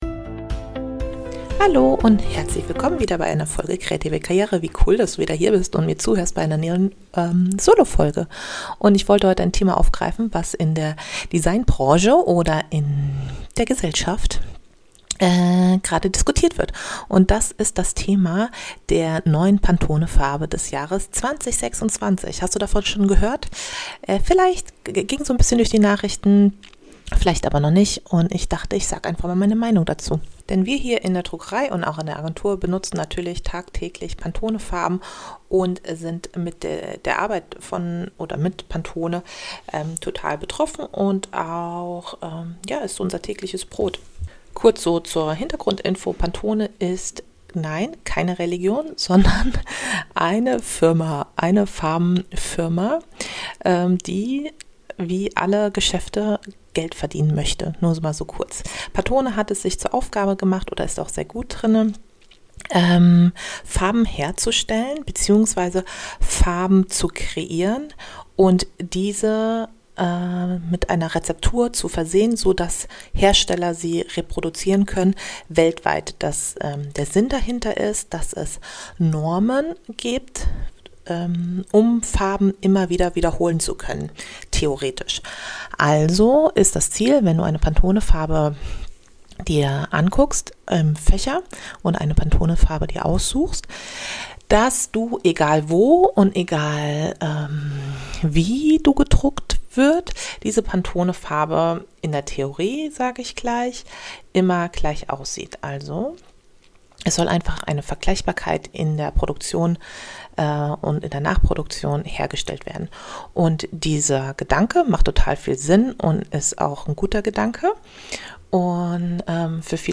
In dieser Solo Folge spreche ich über die neue Pantone Farbe 2026 und das Feedback dazu: · warum Pantone kein neutraler Beobachter, sondern ein Wirtschaftsunternehmen ist · weshalb „Farbe des Jahres“ oft mehr Marketing als Mehrwert ist und warum Cloud Dan...